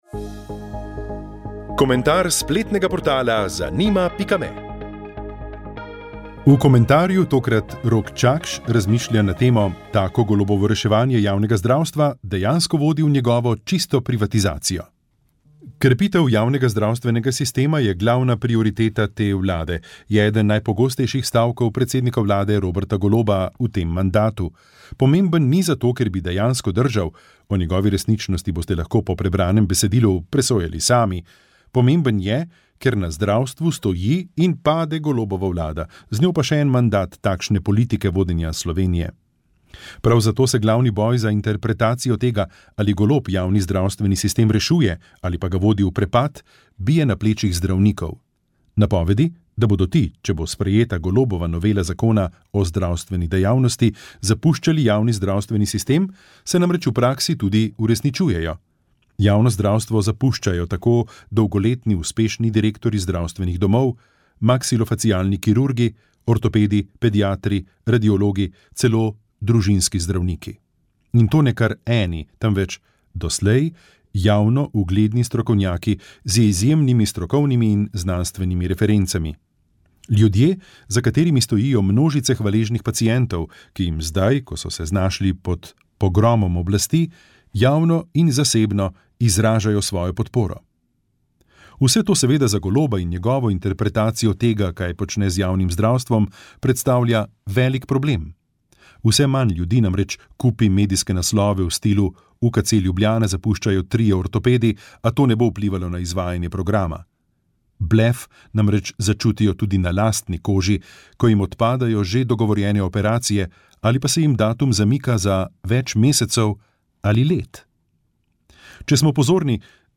Zgodbe za otroke mladi otroci vzgoja šmarnice pravljice